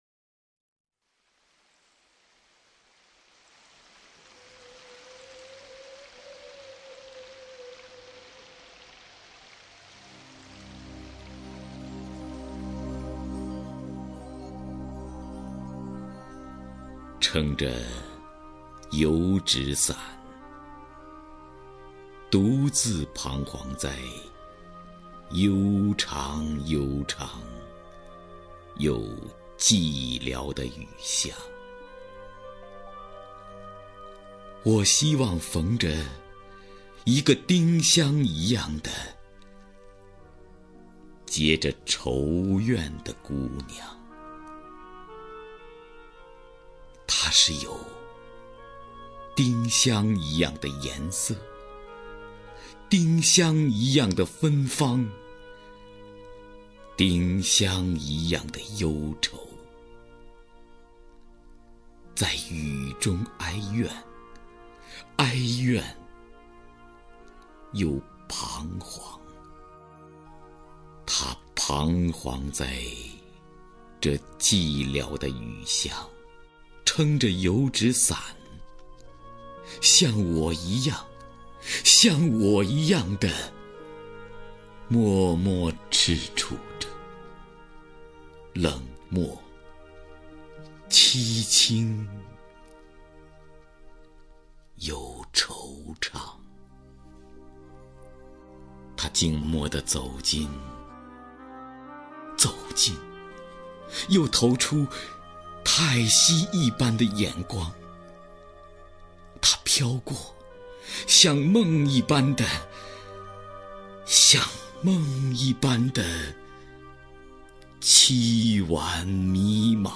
首页 视听 名家朗诵欣赏 徐涛
徐涛朗诵：《雨巷》(戴望舒)